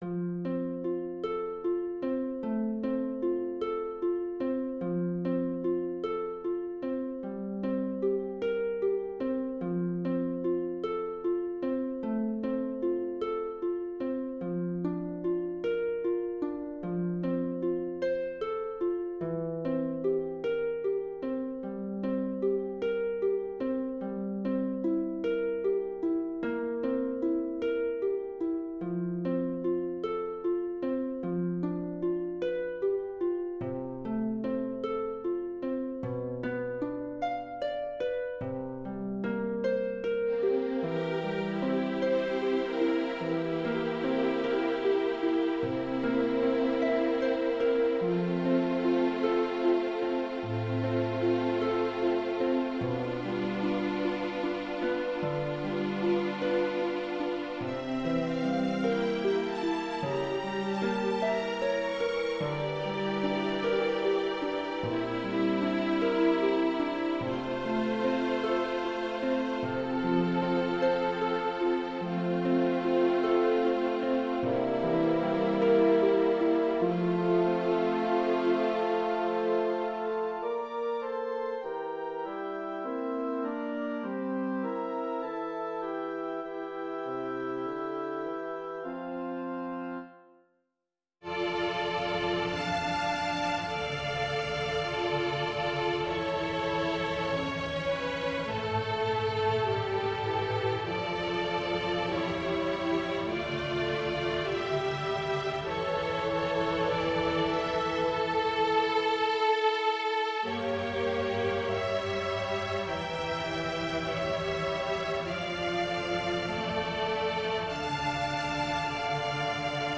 orchestre seul